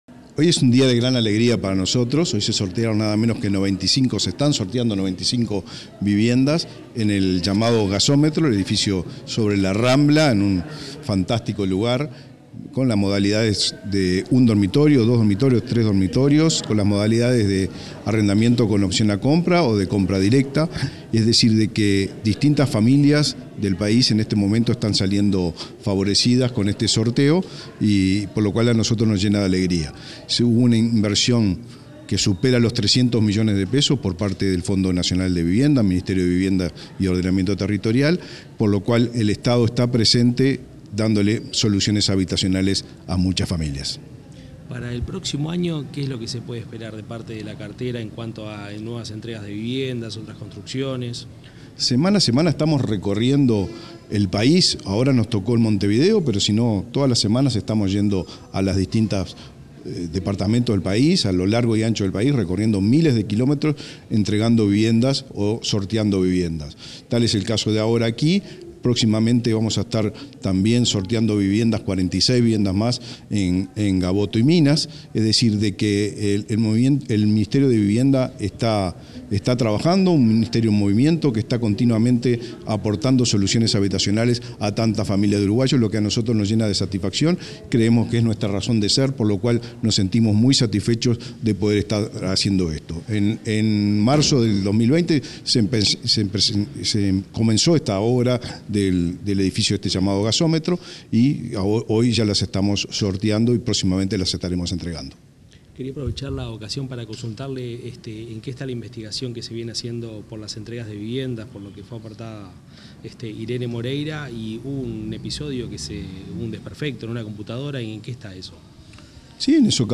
Declaraciones del ministro de Vivienda y Ordenamiento Territorial, Raúl Lozano
Declaraciones del ministro de Vivienda y Ordenamiento Territorial, Raúl Lozano 05/12/2023 Compartir Facebook X Copiar enlace WhatsApp LinkedIn Tras el sorteo de 95 viviendas en el edificio Gasómetro, este 5 de diciembre, el ministro de Vivienda y Ordenamiento Territorial, Raúl Lozano, realizó declaraciones a la prensa.